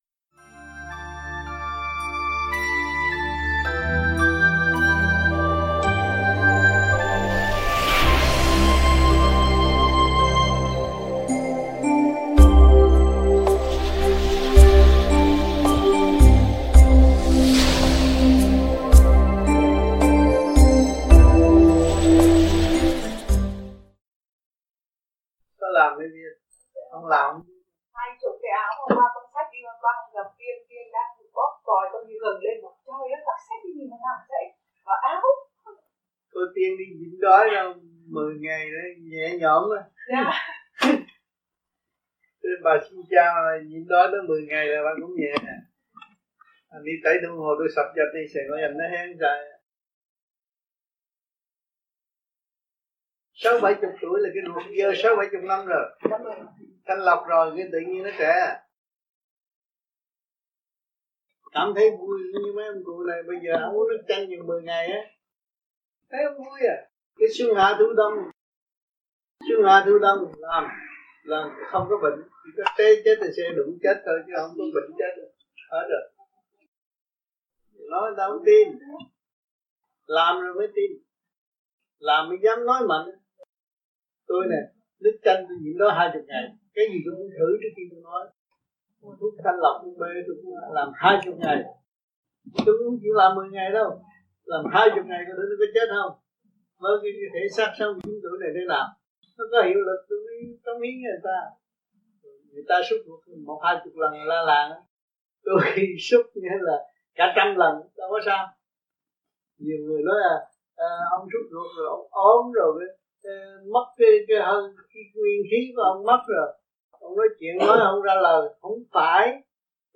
THUYẾT GIẢNG , VẤN ĐẠO